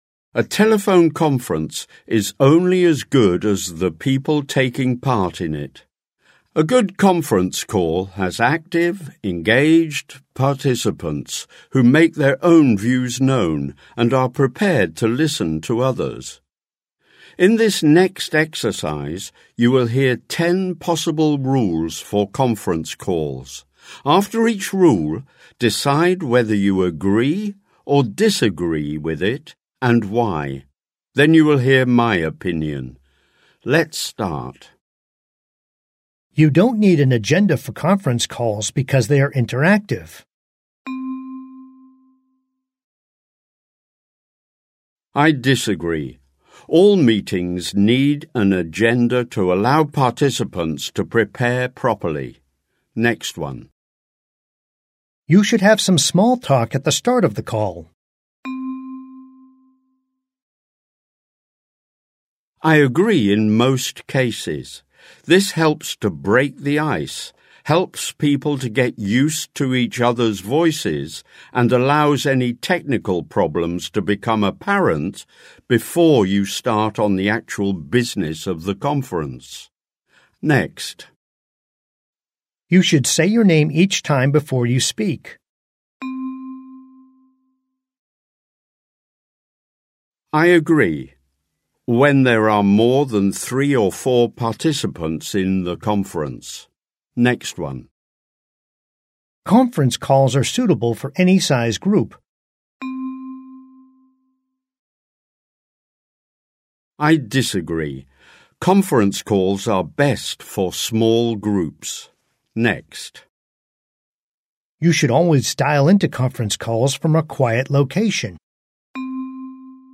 leicht
Audio-Übung